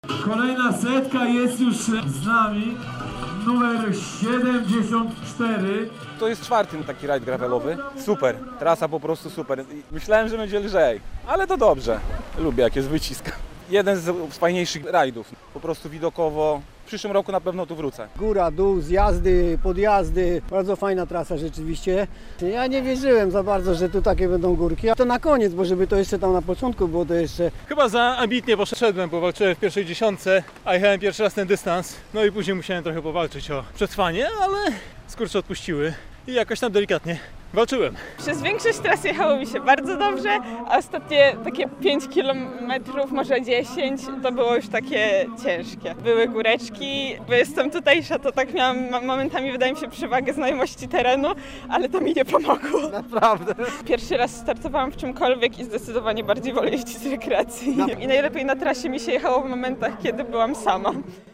Bison Ultra Trail - relacja